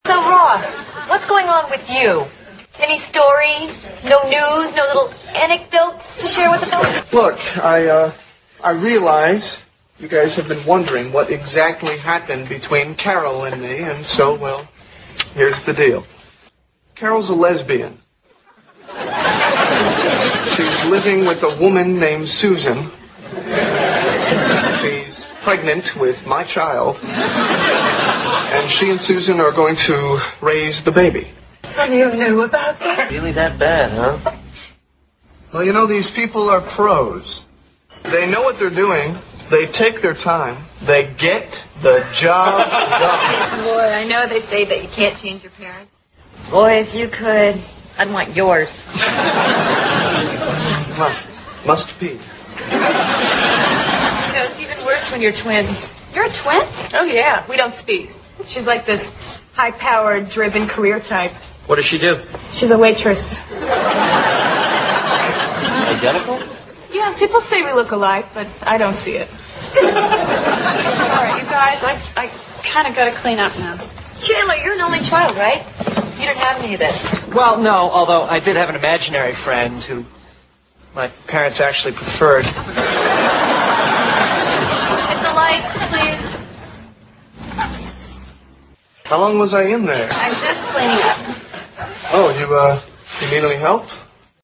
在线英语听力室老友记精校版第1季 第18期:参加助产培训班(6)的听力文件下载, 《老友记精校版》是美国乃至全世界最受欢迎的情景喜剧，一共拍摄了10季，以其幽默的对白和与现实生活的贴近吸引了无数的观众，精校版栏目搭配高音质音频与同步双语字幕，是练习提升英语听力水平，积累英语知识的好帮手。